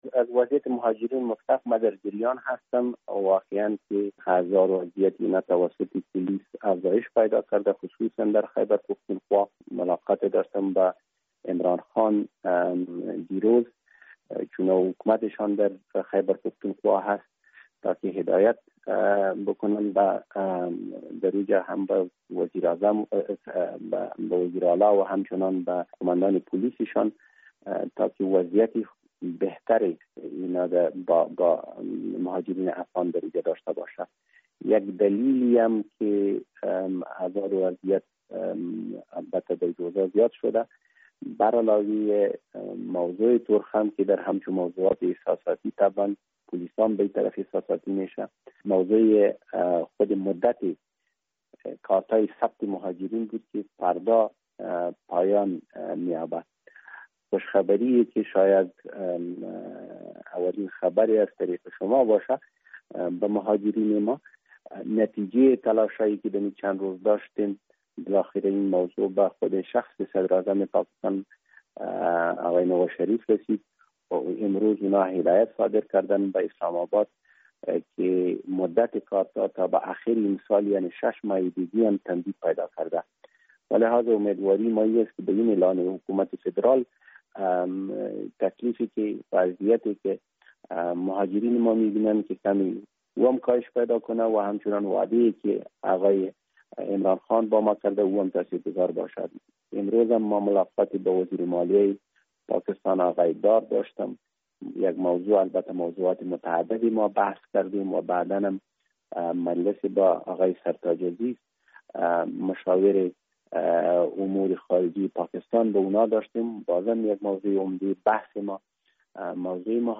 مصاحبه با حضرت عمر زاخیلوال سفیر افغانستان در اسلام آباد: